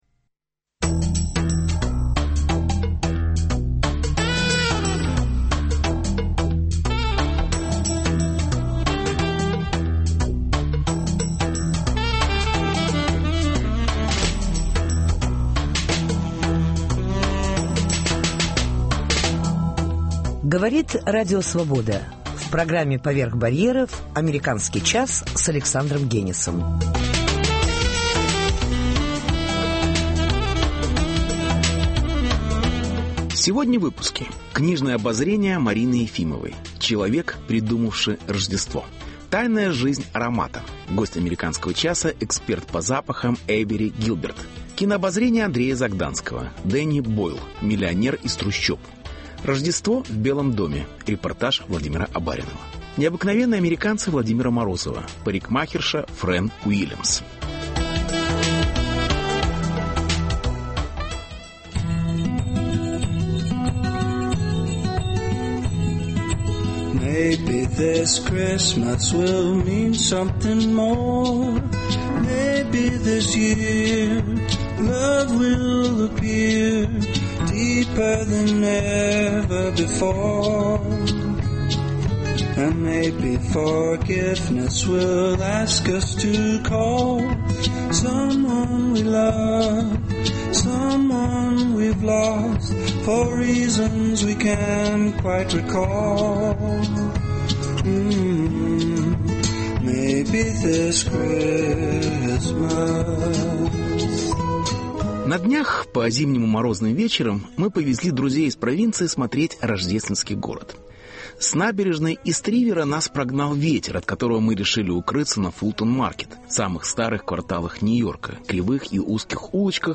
Интервью. Тайная жизнь аромата.